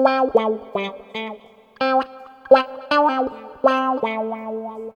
134 GTR 2 -L.wav